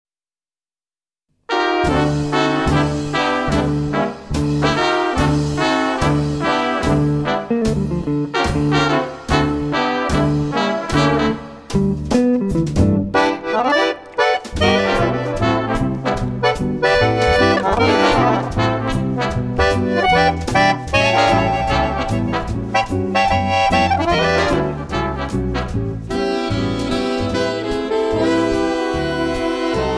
big band
swing music